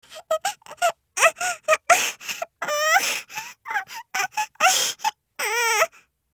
Grumpy Crying Baby 1 Sound Button - Free Download & Play